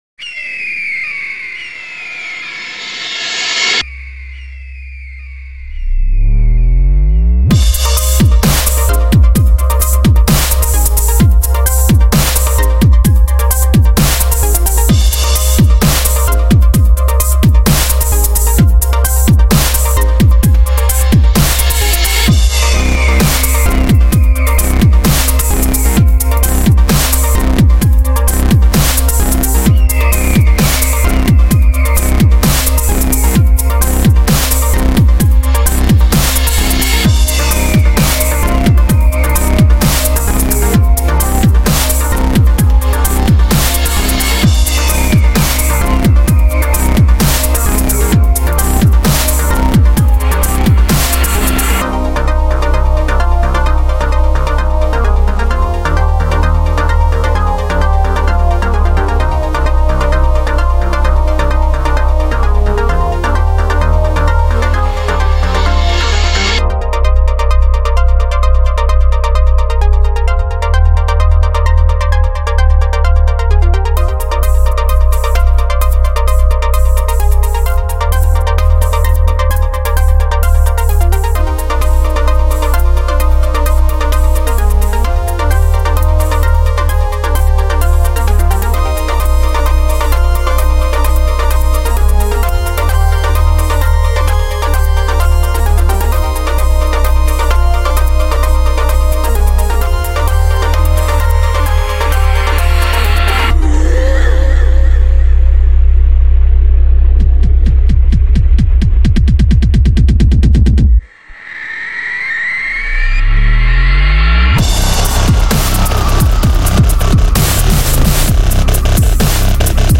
Ah well some uh, "dubstep" for you.